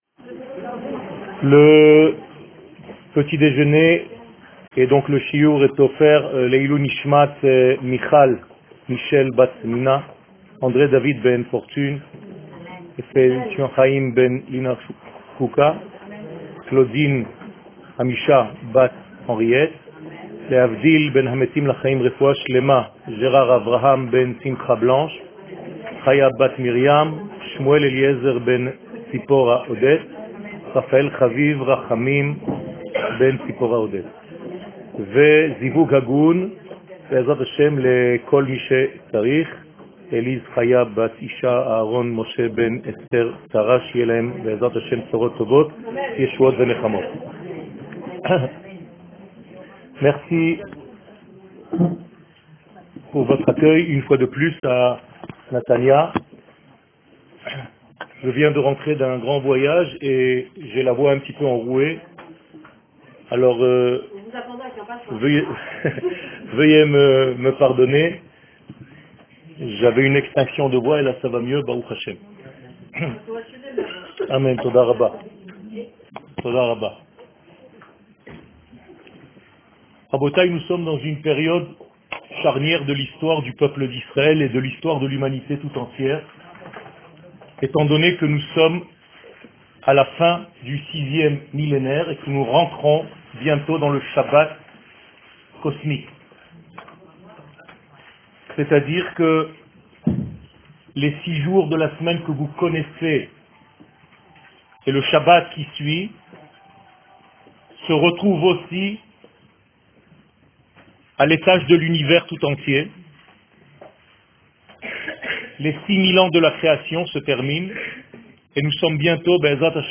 Emounat Israel - Natanya Identite שיעור מ 12 דצמבר 2018 01H 22MIN הורדה בקובץ אודיו MP3 (37.73 Mo) הורדה בקובץ אודיו M4A (9.63 Mo) TAGS : Secrets d'Eretz Israel Etude sur la Gueoula Emouna Torah et identite d'Israel שיעורים קצרים